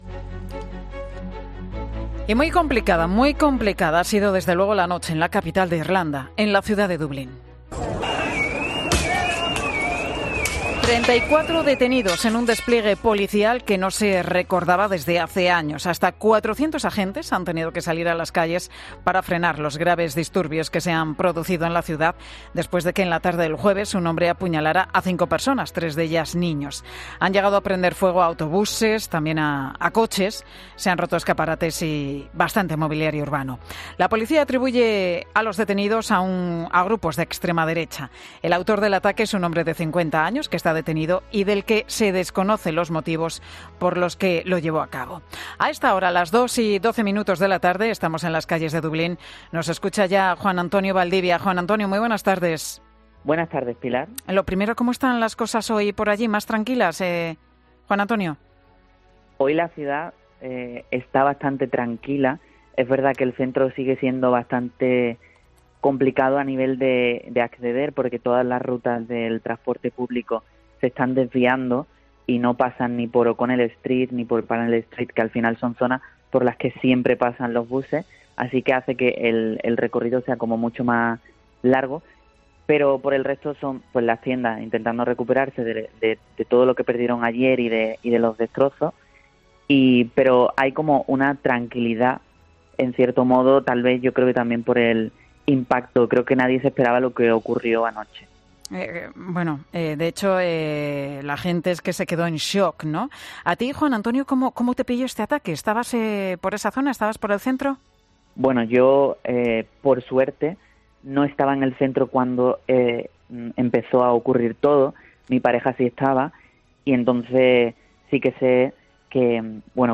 "El centro de Dublín está blindado y en calma": el testimonio